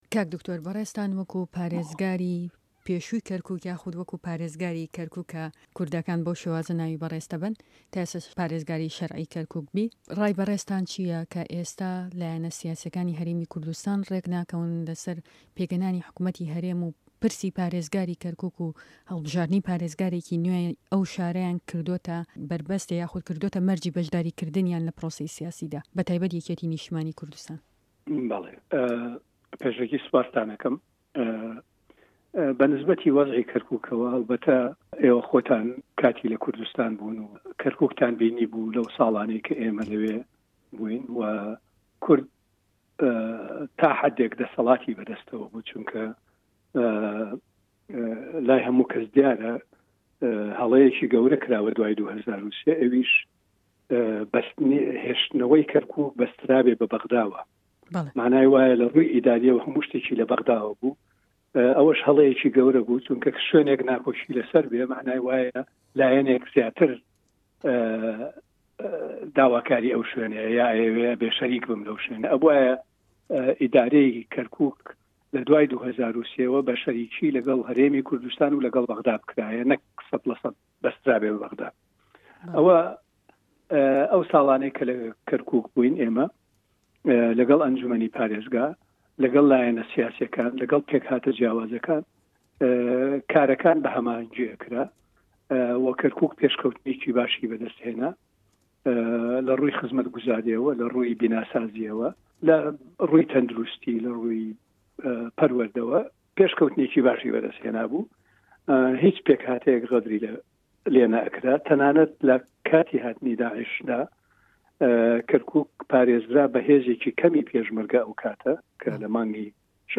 Dr. Necmedîn Kerîm parêzgarê Kerkuk yê ji kar avêtî di hevpeyvînek taybet de
Hevpeyvîn li gel Dr. Necmedîn Kerîm